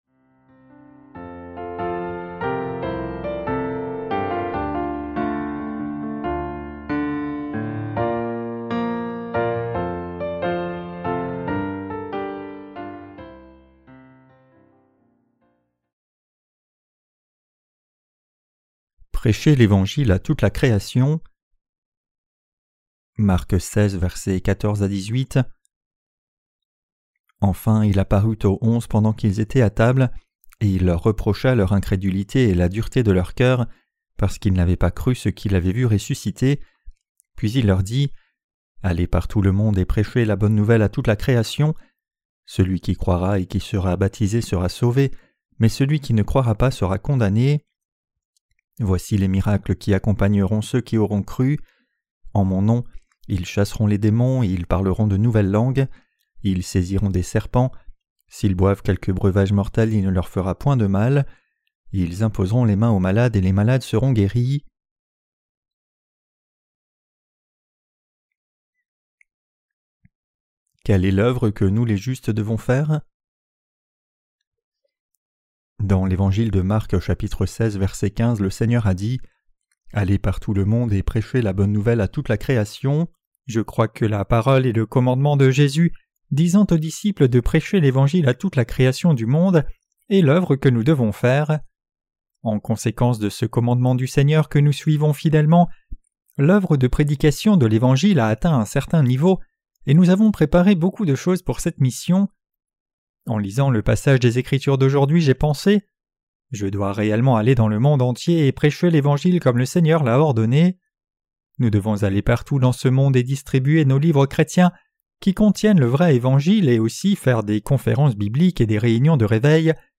Sermons sur l’Evangile de Marc (Ⅲ) - LA BÉNÉDICTION DE LA FOI REÇUE AVEC LE CŒUR 13.